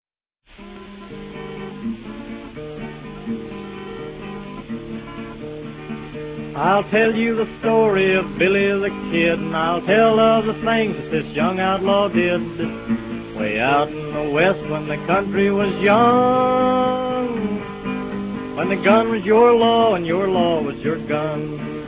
Recorded in New York between 1944 and 1949.